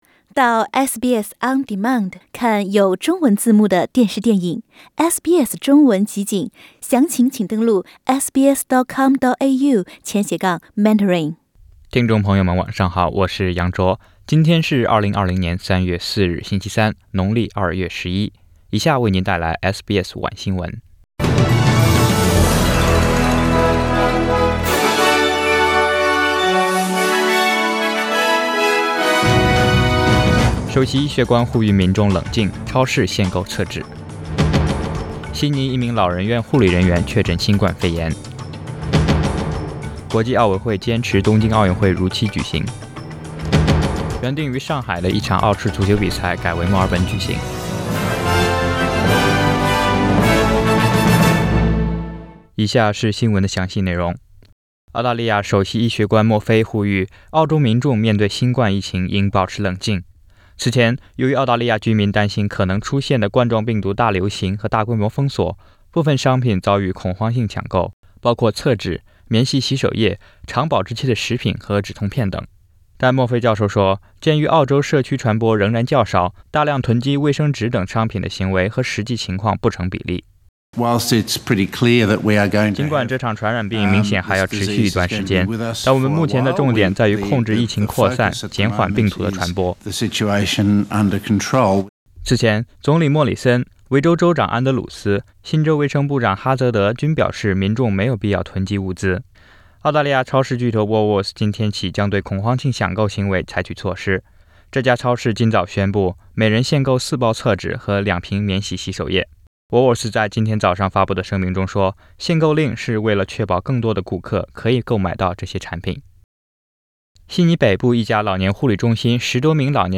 SBS晚新闻（3月4日）